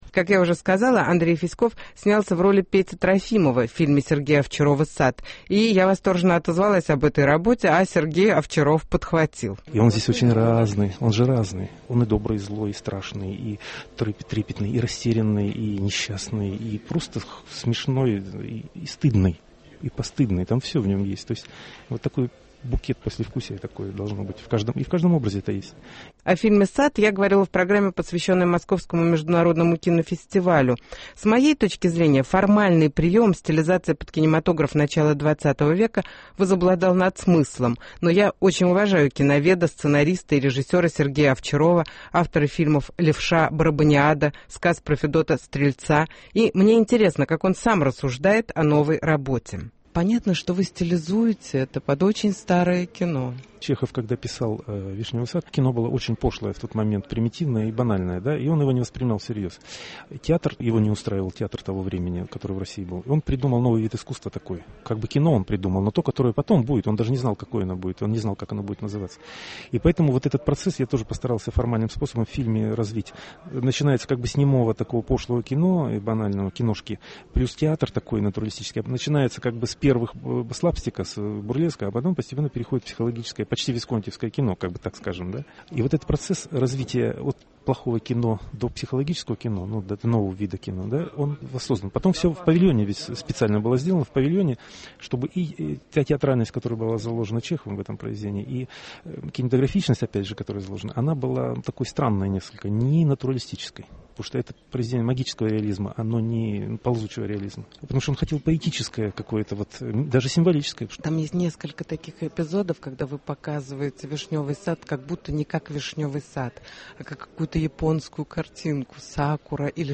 Интервью с режиссером Сергеем Овчаровым